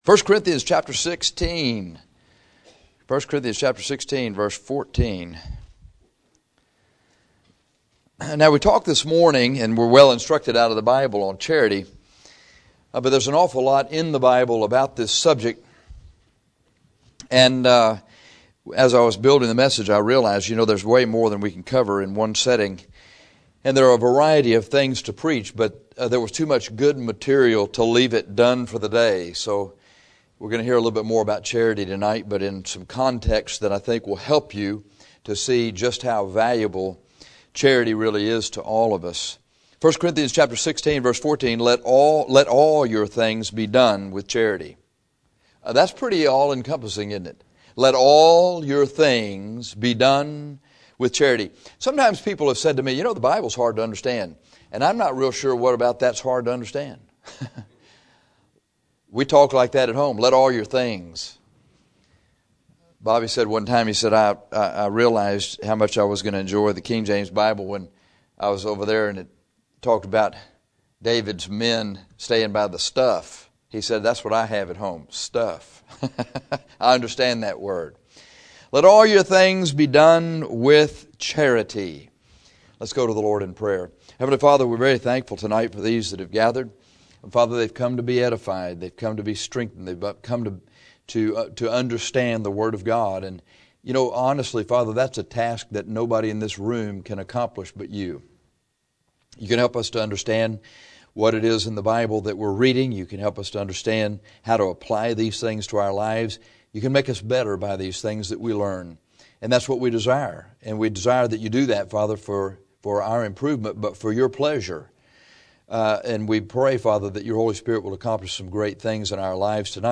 This sermon shows what charity does. Paul showed us in 1 Cor 13 that without charity we are nothing.